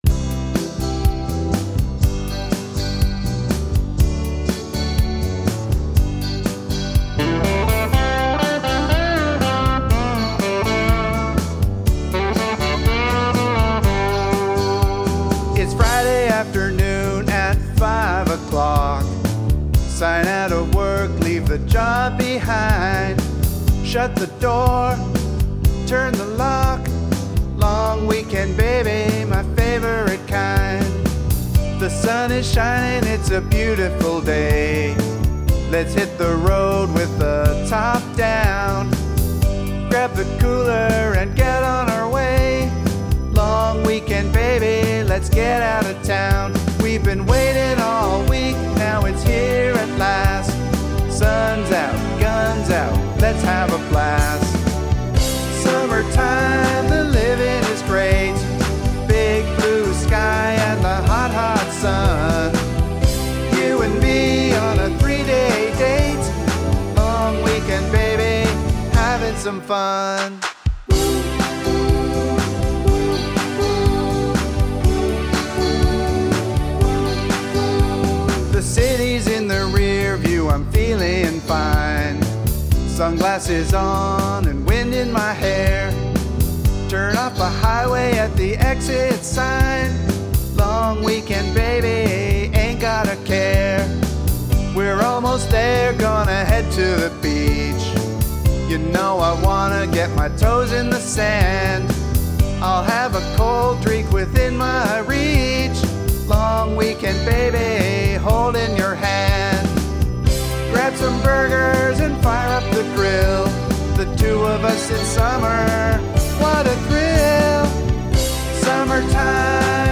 Long Weekend, Baby May-June 2025June 2025 I decided to try to write a candidate for Song Of The Summer.